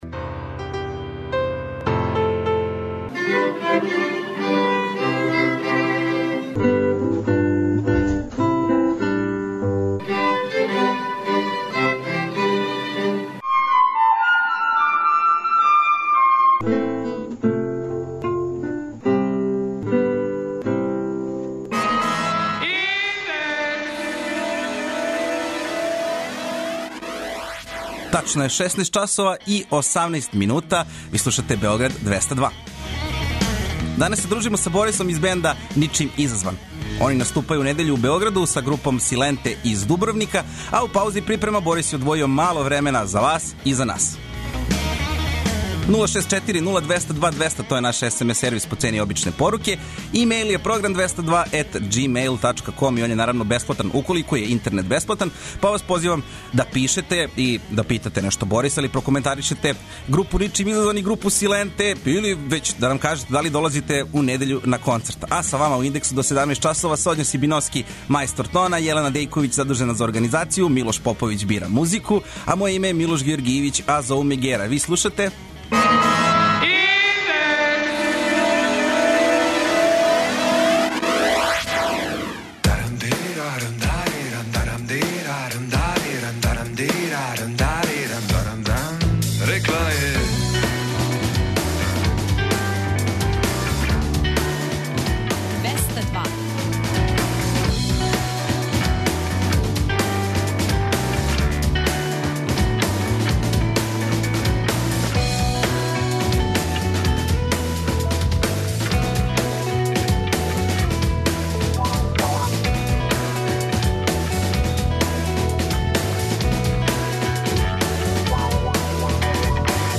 Данас нам у госте долазе чланови групе 'Ничим изазван'.